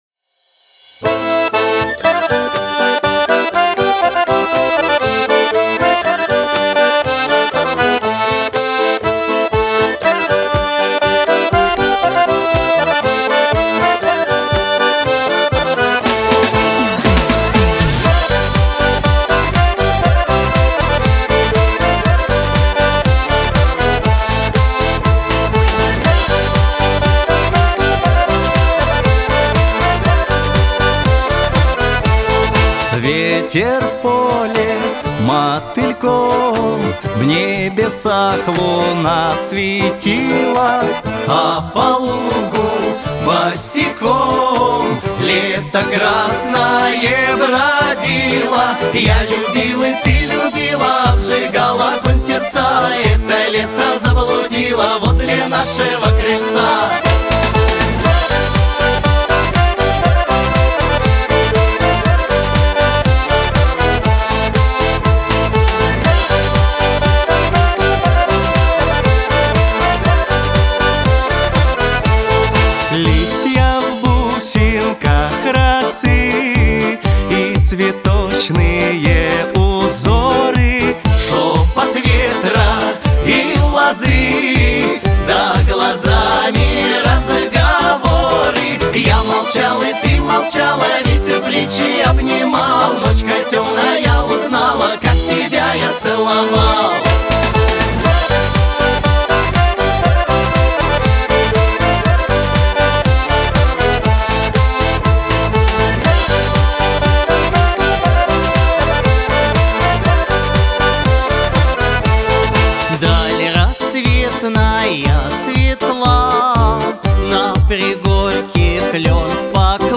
• Категория: Детские песни
народный мотив